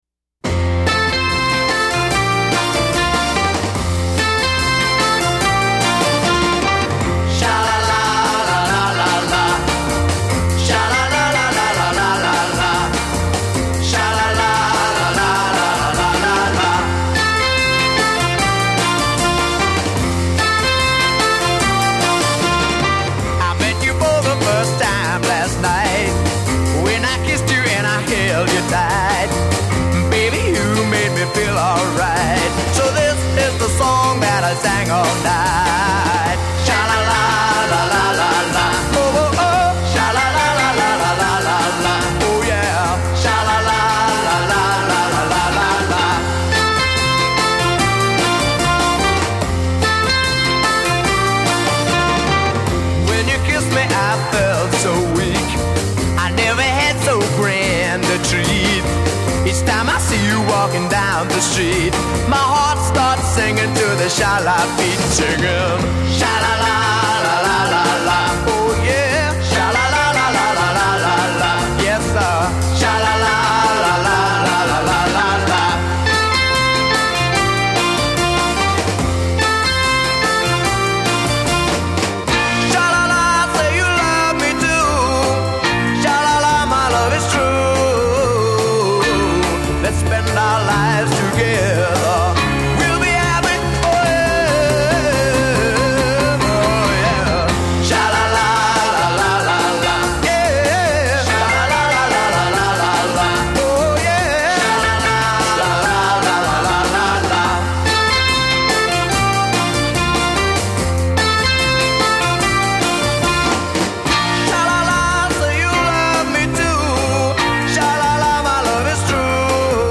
Additional studio musicians were also probably involved.
C Bridge : 9 Solo vocal; text from hook with new melody d
A Refrain   12+ As in refrains above; repeat and fade a